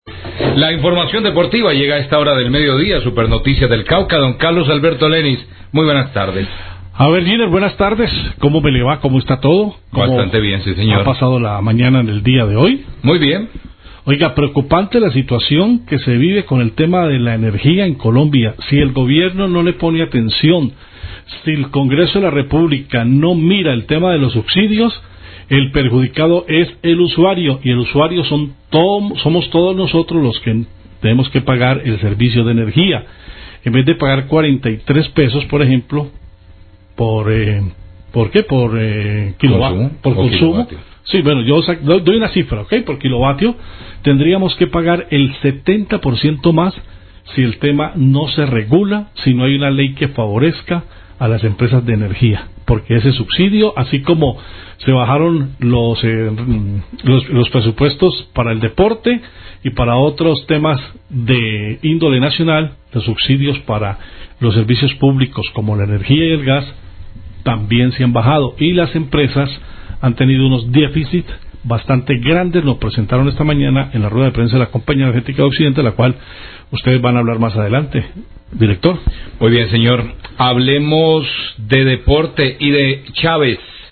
PERIODISTA DEPORTIVO COMENTA SOBRE ALZA EN TARIFAS SI SE ELIMINAN LOS SUBSIDIOS
Radio